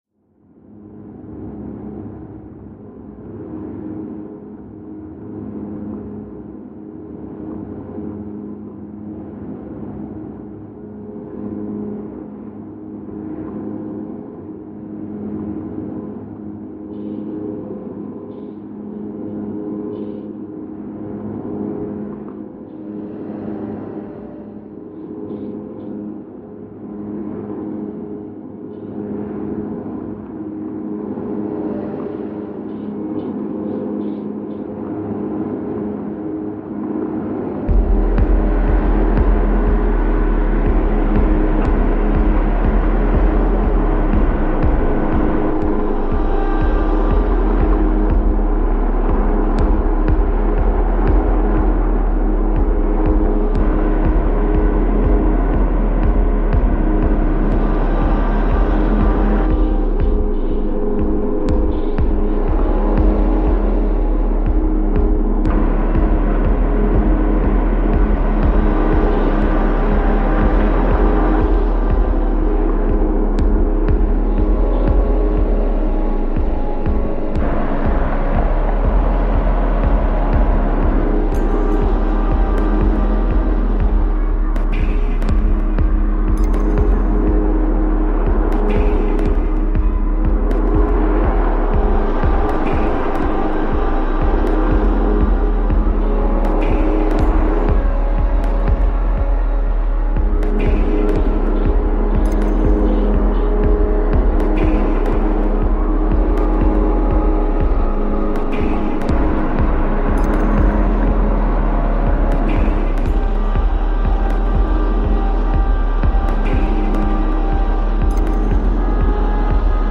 remixes our sound from the Clifton suspension bridge